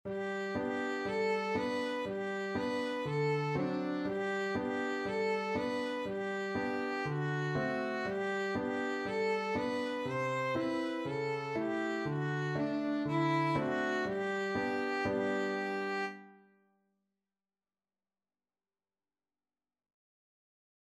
Free Sheet music for Violin
4/4 (View more 4/4 Music)
G major (Sounding Pitch) (View more G major Music for Violin )
Traditional (View more Traditional Violin Music)